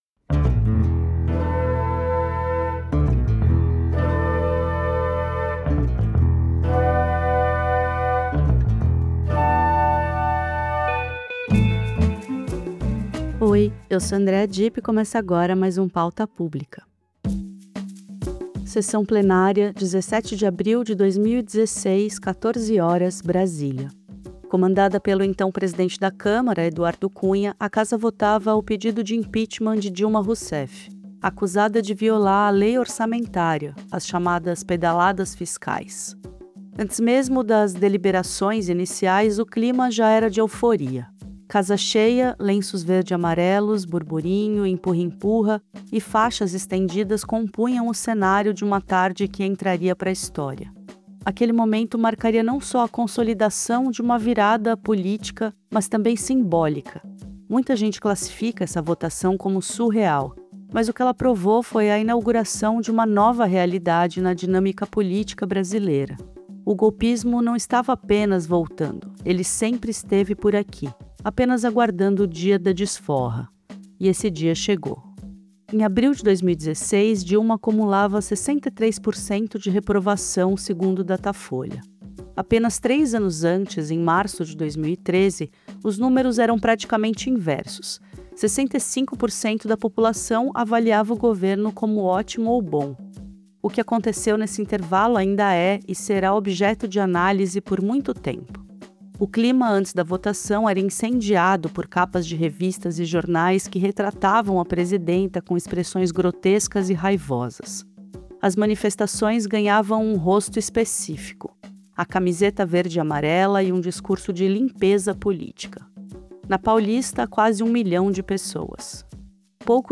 O ex-deputado federal Jean Wyllys relembra o dia em que inaugurou uma nova realidade na dinâmica política brasileira.